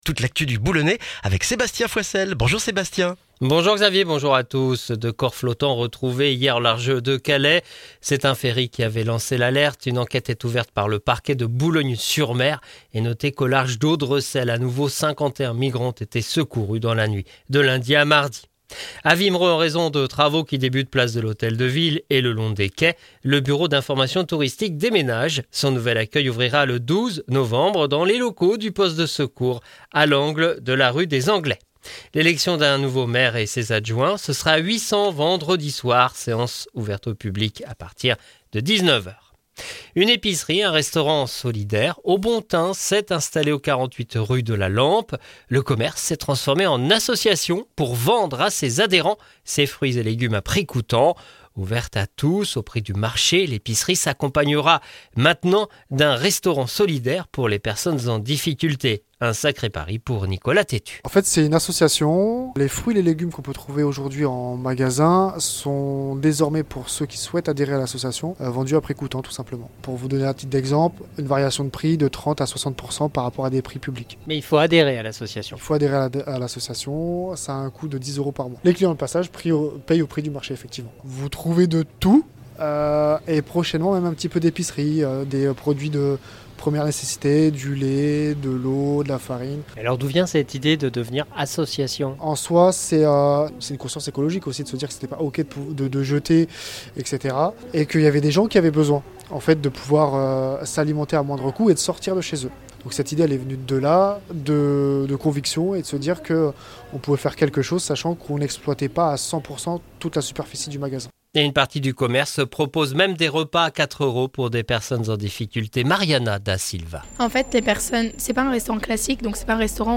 Le journal du mercredi 6 novembre dans le Boulonnais